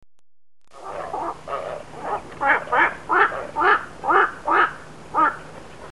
Nitticora – Sgàrzo
Nycticorax nycticorax
Il richiamo è un gutturale gracchiare simile a quello del Corvo Imperiale: ‘uok’, ‘quark’, ‘uak’ o ‘guak’, normalmente emessi in volo.
Nitticora.mp3